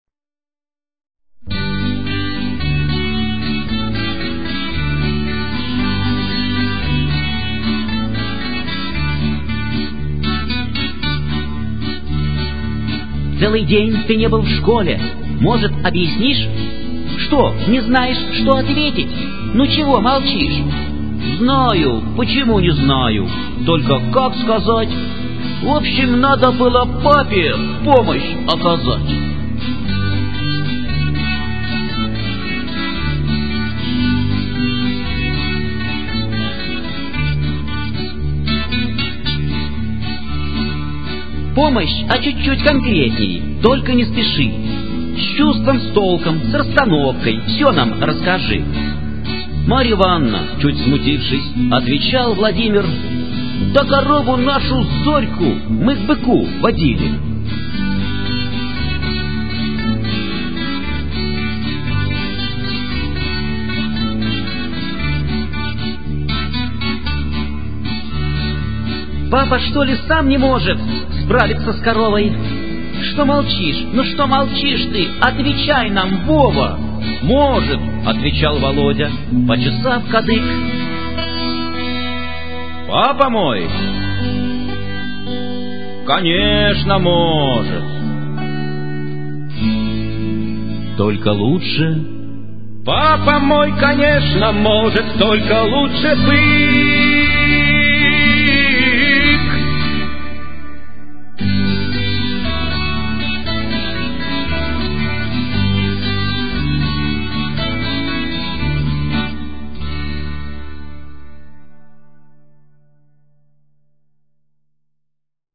~ Песни под гитару ~